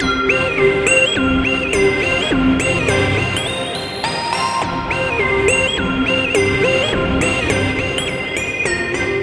摇滚大鼓104 4 Bar Hatz
描述：直接的摇滚乐节拍。
Tag: 104 bpm Rock Loops Drum Loops 1.55 MB wav Key : Unknown